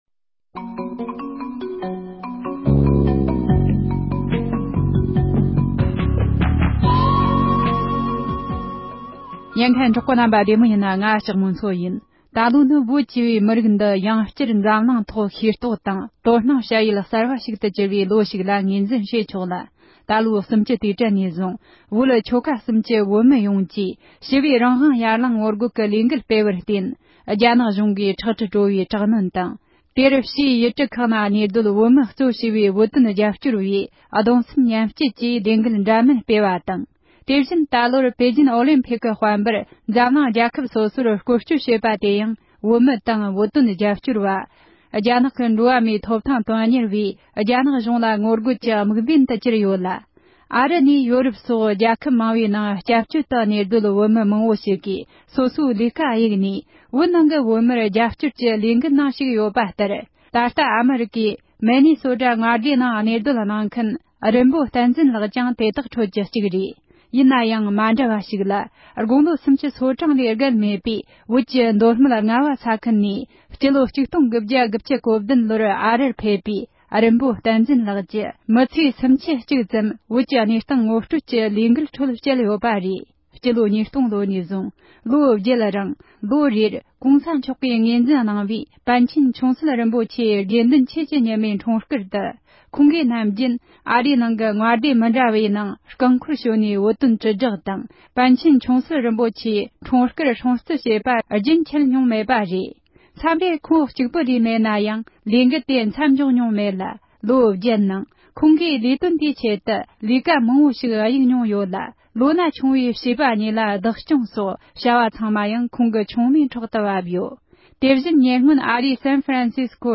བོད་དོན་ལས་འགུལ་སྐོར་གླེང་མོལ།